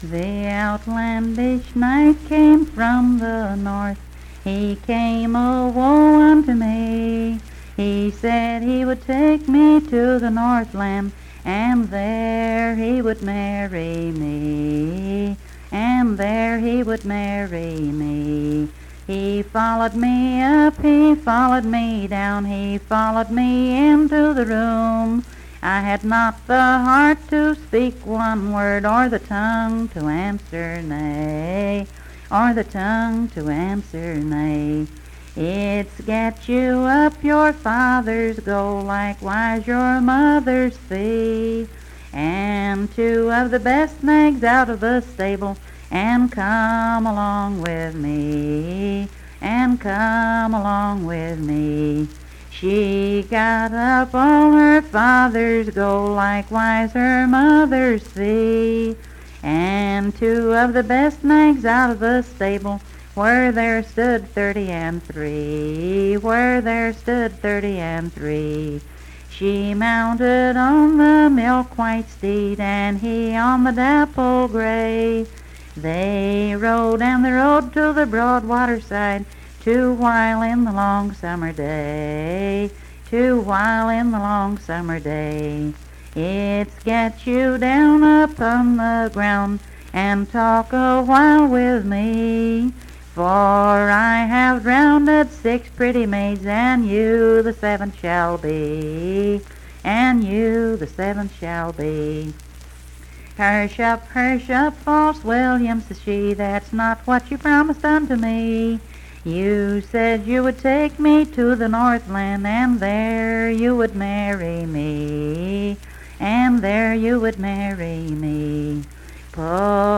Unaccompanied vocal music performance
Verse-refrain 20(5w/R).
Voice (sung)